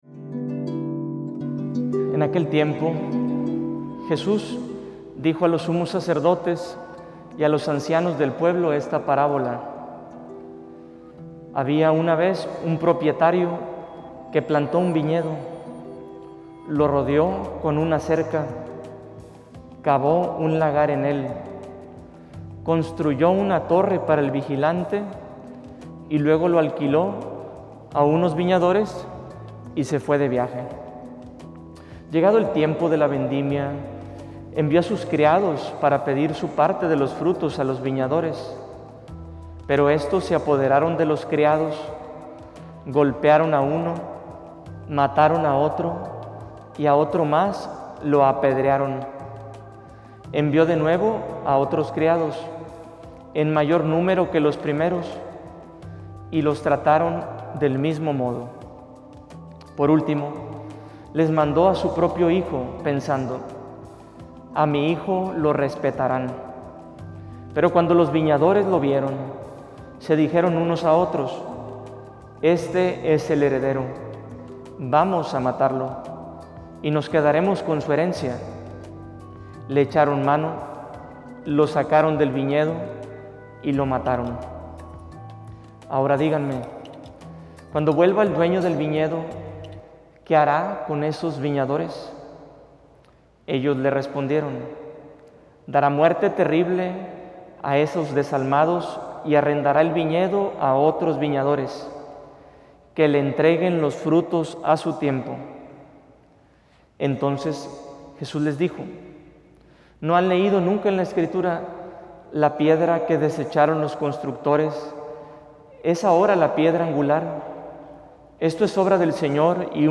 Homilia_Lo_que_hace_tu_amor_en_mi_Senor.mp3